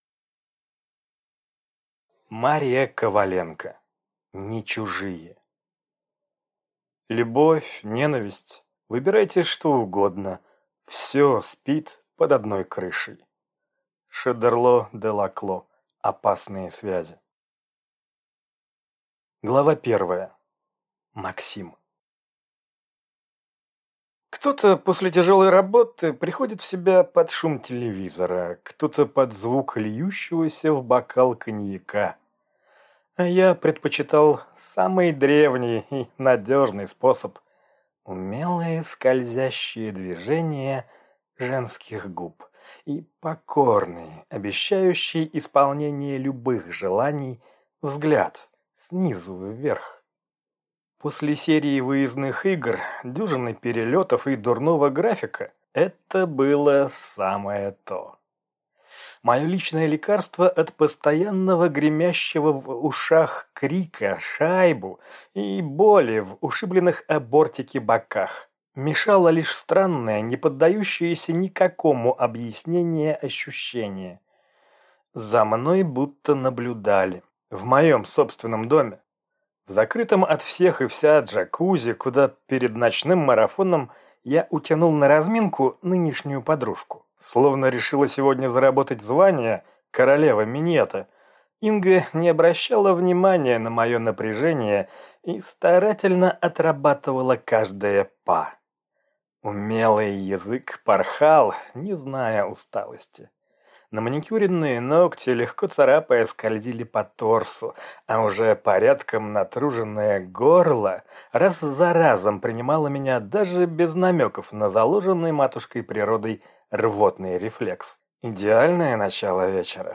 Аудиокнига Не чужие | Библиотека аудиокниг